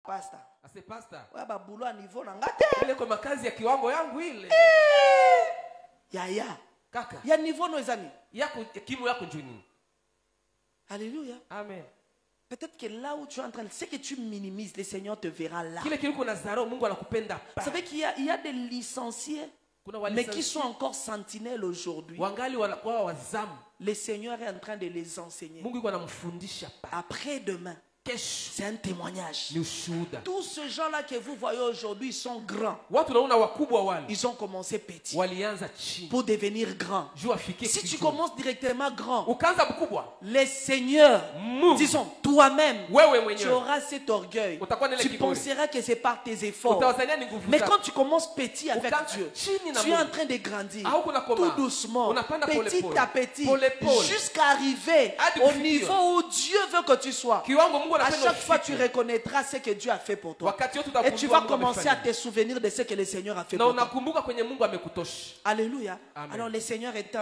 Sermon Media
sermon1.mp3